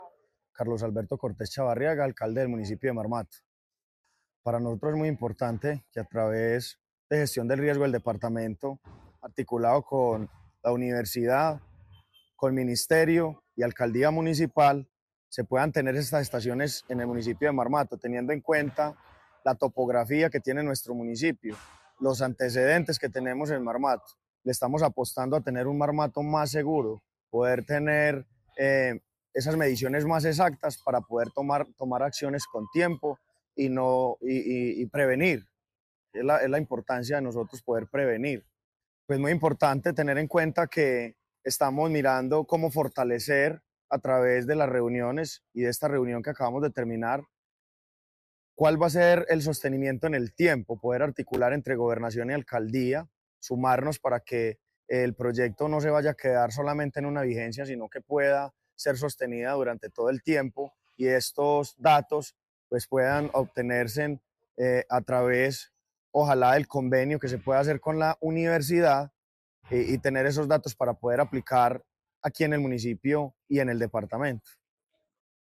Carlos Alberto Cortés Chavarriaga, alcalde de Marmato